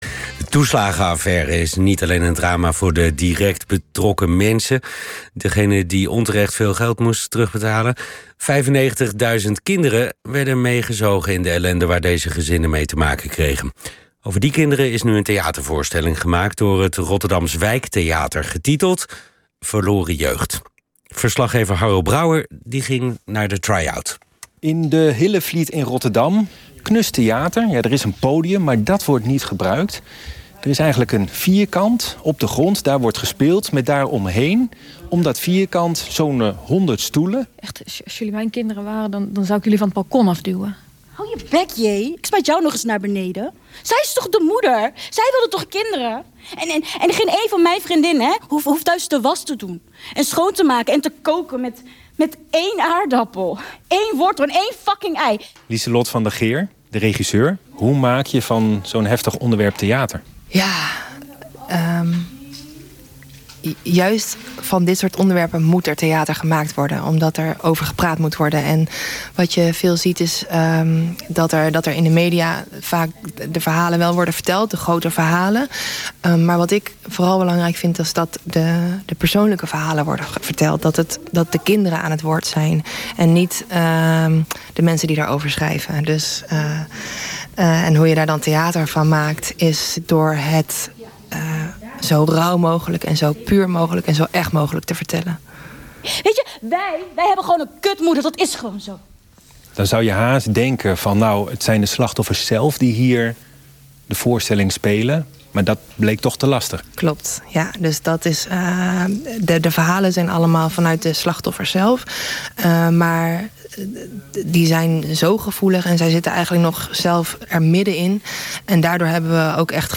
“Voor het eerst kreeg ik een idee van hoe het geweest moet zijn voor die kinderen” Beluister hier het radiofragment over Verloren Jeugd bij de NOS, juni 2024
NOS-radio-Verloren-Jeugd.mp3